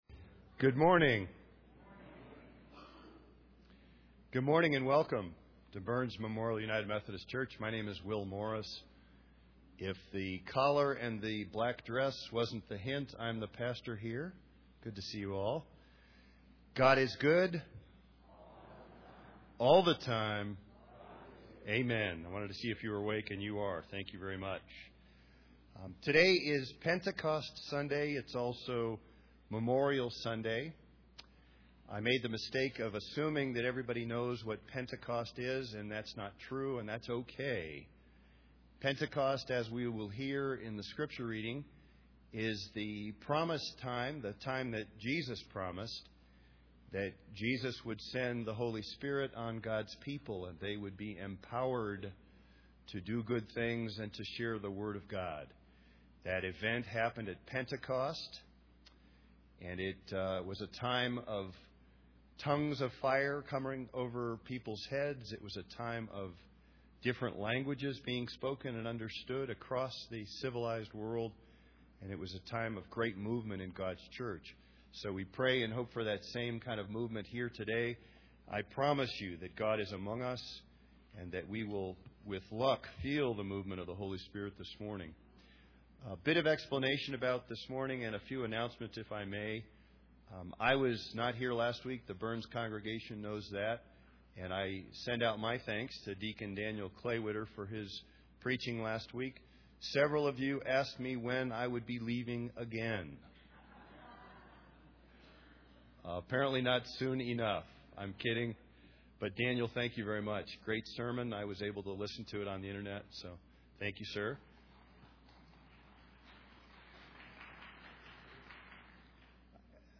Pentecost Sunday Worship Service
Announcements                                                             Pastor
01WelcomeandAnnouncements.mp3